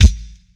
Boom-Bap Kick 82.wav